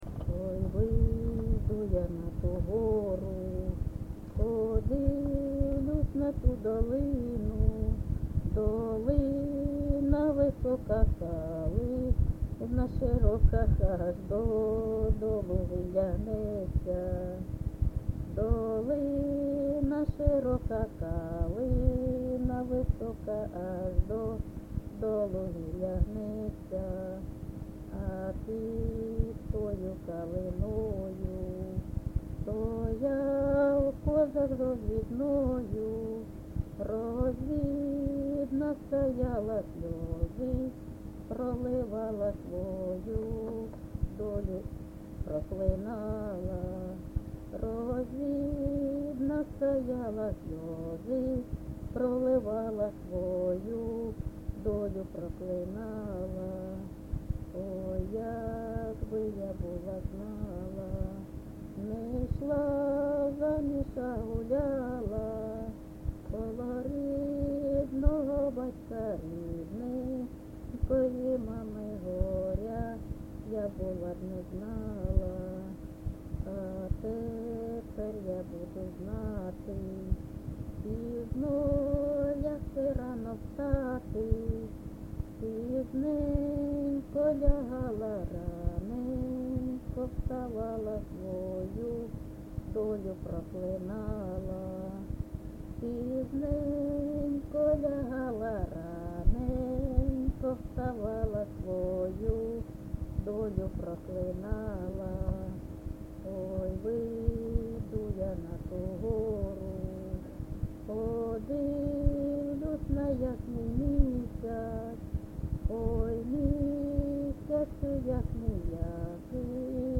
ЖанрПісні з особистого та родинного життя
Місце записус. Хрестівка, Горлівський район, Донецька обл., Україна, Слобожанщина